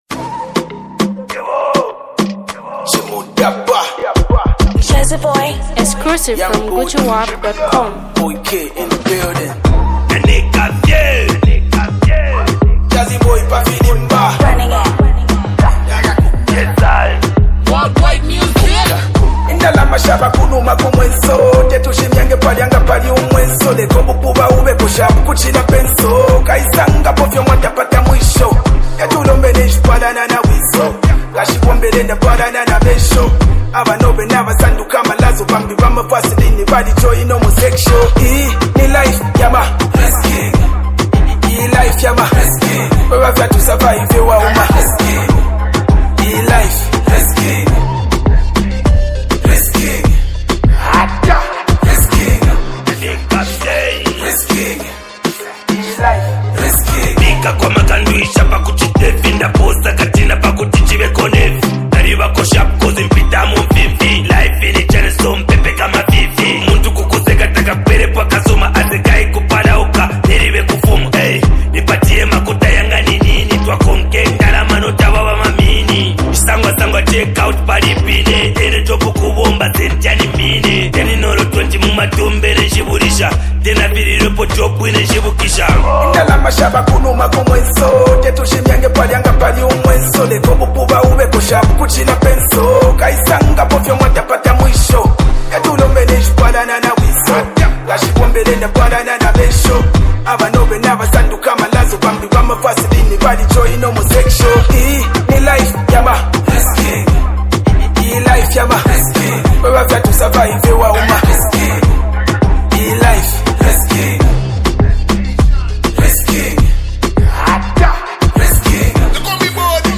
high-octane collaboration
heavyweight rapper
adrenaline-fueled track
passion of Zambian hip-hop.